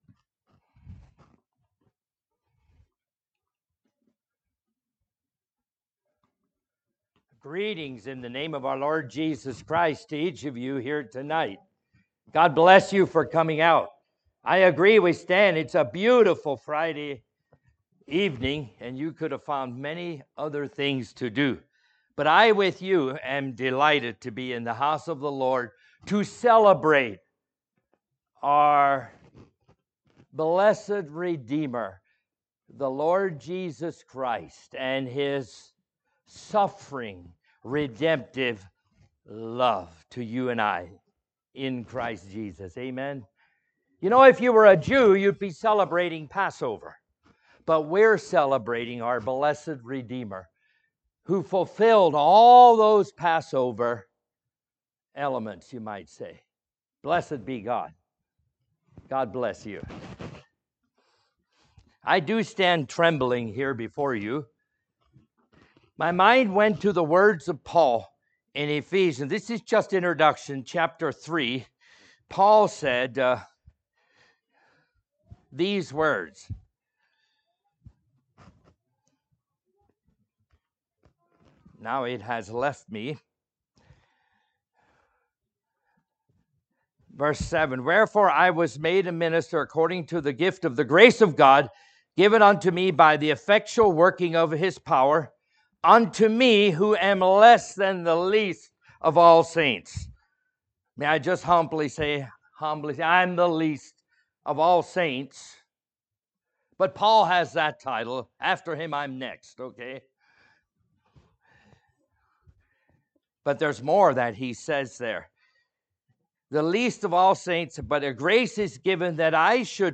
Listen to sermon recordings from Word of Life Mennonite Fellowship.